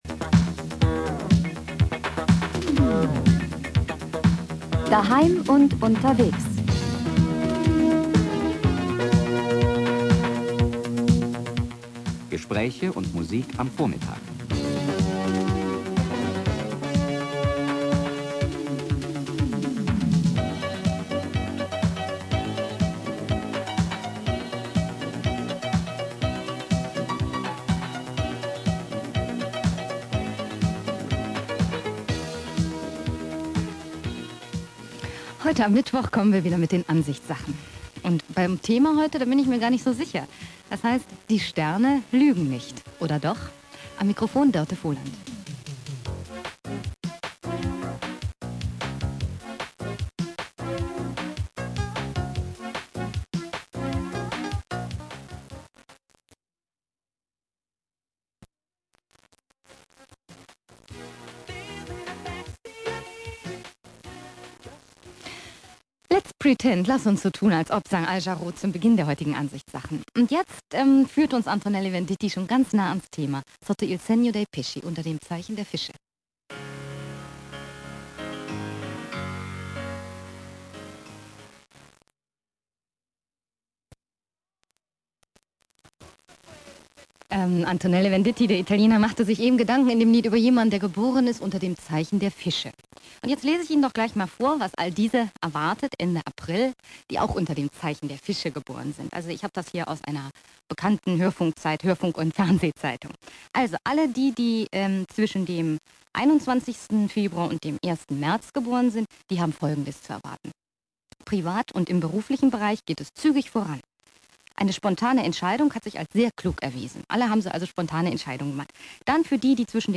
Streitgespräch Prof. Heinz Haber
Musik herausgeschnitten, reine Redezeit etwa 70 Minuten.